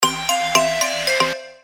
без слов
короткие
Просто интересный звучок для сообщений